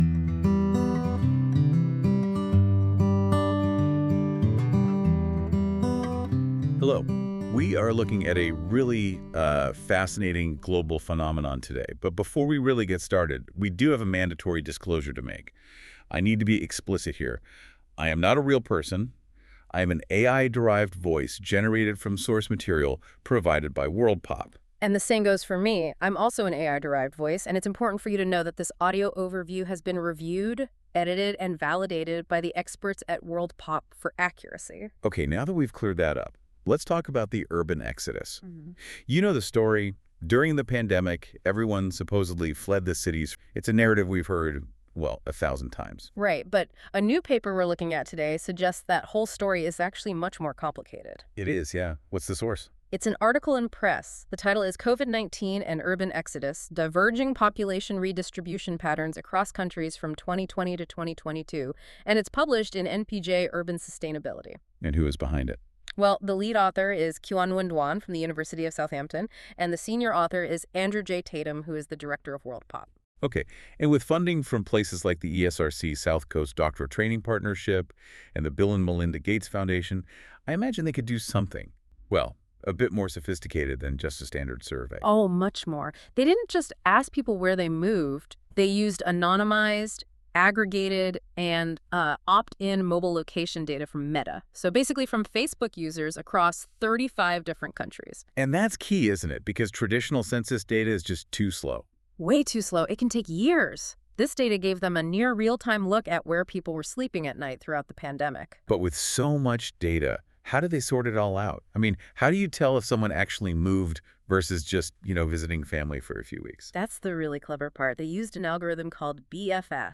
This feature uses AI to create a podcast-like audio conversation between two AI-derived hosts that summarise key points of the document - in this case the full journal article linked above.
Music: My Guitar, Lowtone Music, Free Music Archive (CC BY-NC-ND)